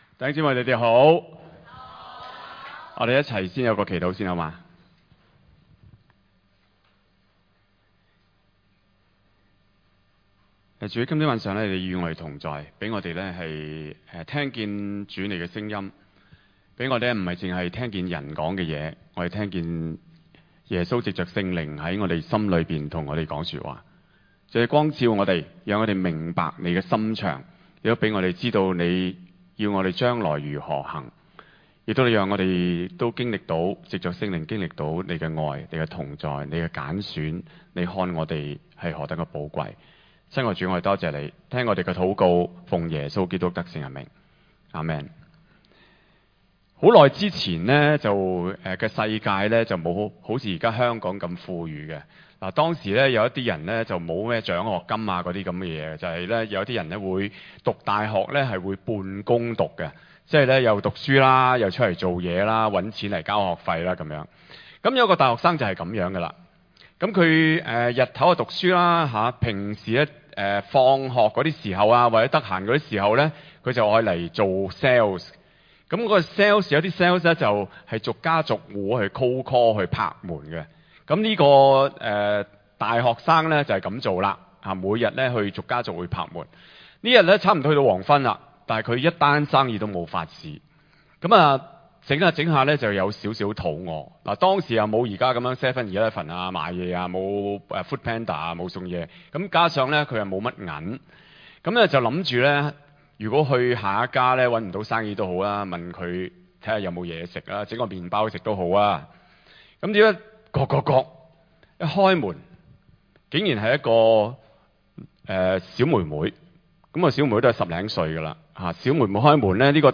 場所：週六崇拜